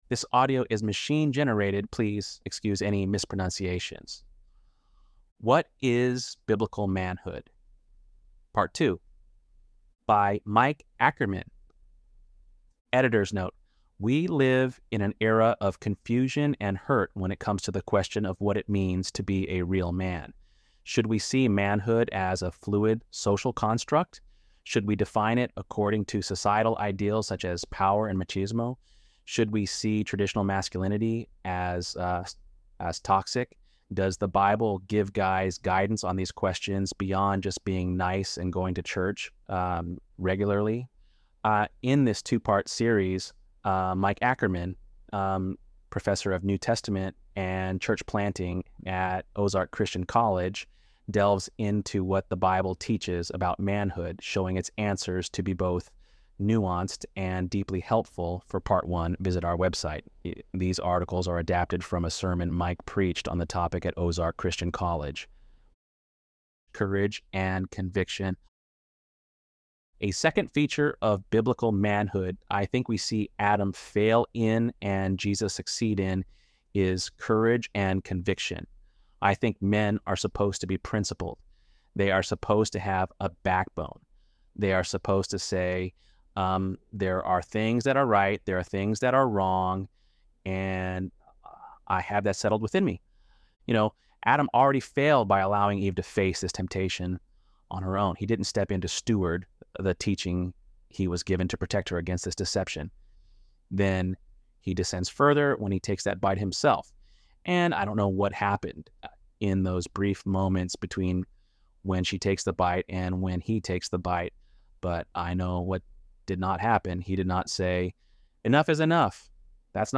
ElevenLabs_7.1_Manhood_2.mp3